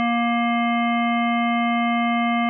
You can create your own simulated clarinet sound s(t) as follows:
s(t) = sin(w1t) + 0.75*sin(3*w1t) + 0.5*sin(5*w1t) + 0.14*sin(7*w1t) + 0.5*sin(9*w1t) + 0.12*sin(11*w1t) + 0.17*sin(13*w1t)
Simulated Clarinet WAV file (fundamental = 235.5 Hz).